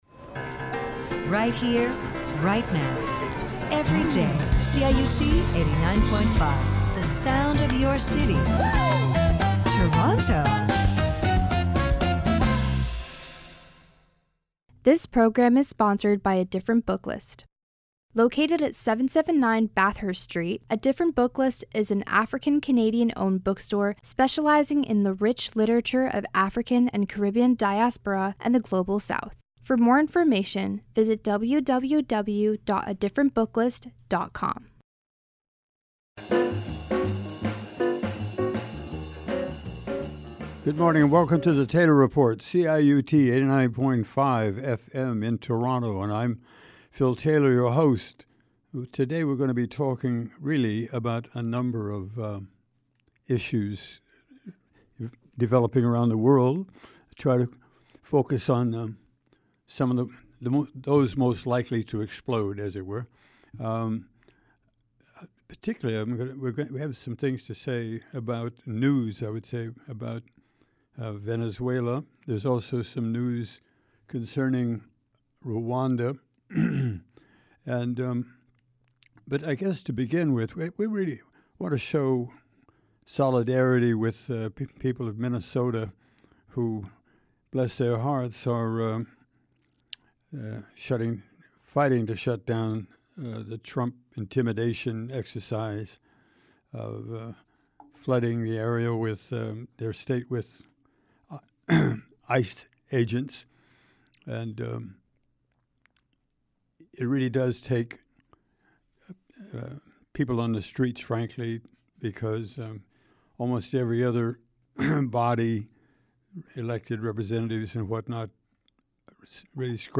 Taylor Report commentary